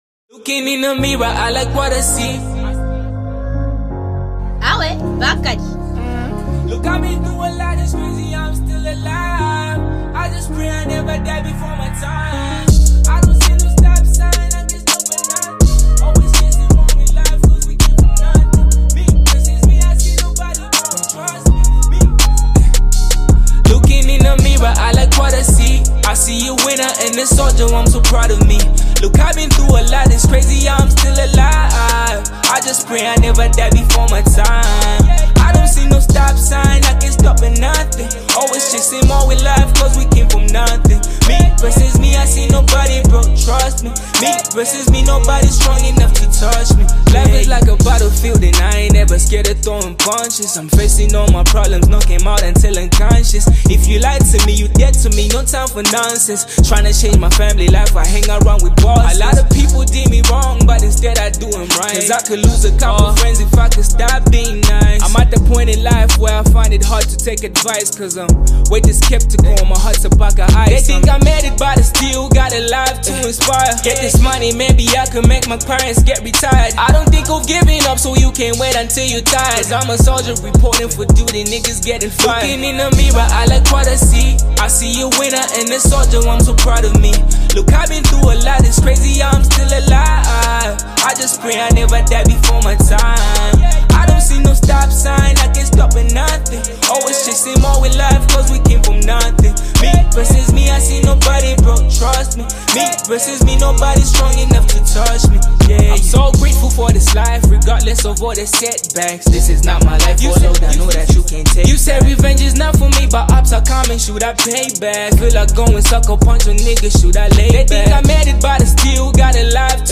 Zambia's highly acclaimed singer